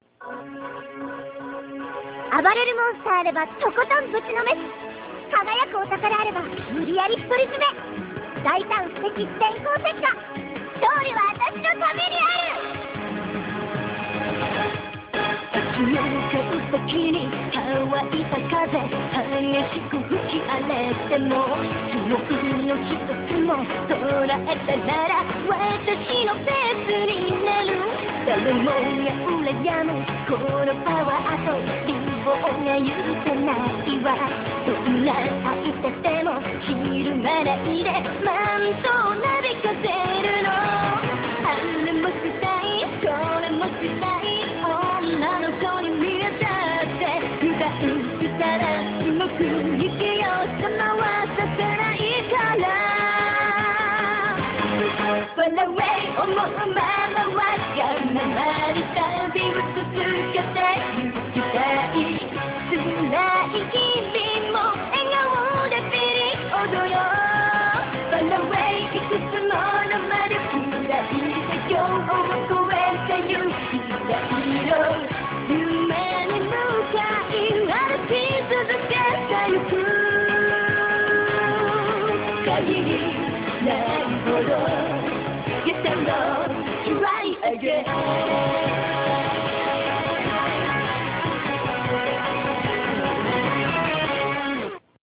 Vocals: